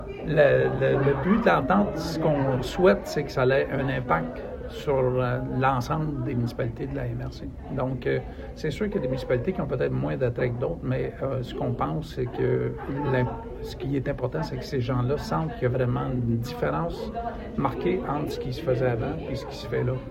La MRC de La Haute‑Yamaska a annoncé mardi, lors d’une conférence de presse, la conclusion d’une nouvelle entente d’une durée d’un an en matière de développement touristique avec Commerce Tourisme Granby région (CTGR).
René Beauregard, préfet de la MRC de La Haute‑Yamaska, était sur place.